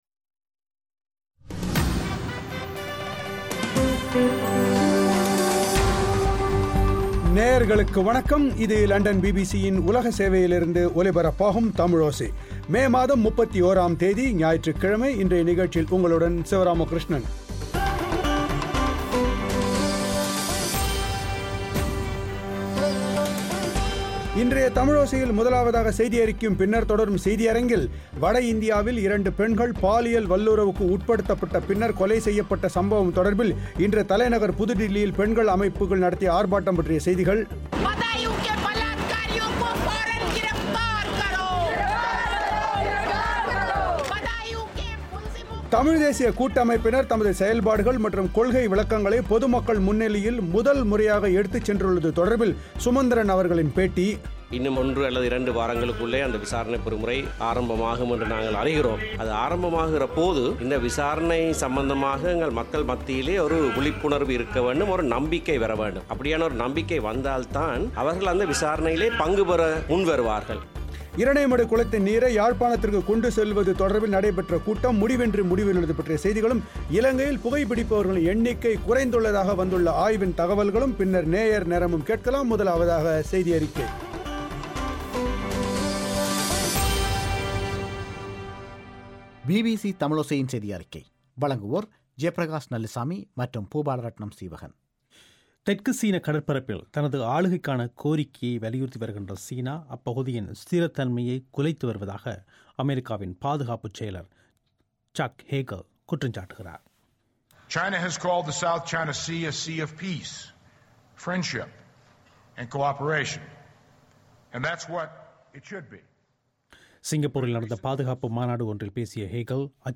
ஜெனீவாத் தீர்மானம் தொடர்பில், தமிழ்த் தேசியக் கூட்டமைப்பினர் வடக்கு-கிழக்கு மக்களுக்கு விளக்கமளிக்கும் செயல்திட்டத்தை முன்னெடுத்துள்ள விபரங்கள், அது தொடர்பில் சுமந்திரன் அவர்களின் பேட்டி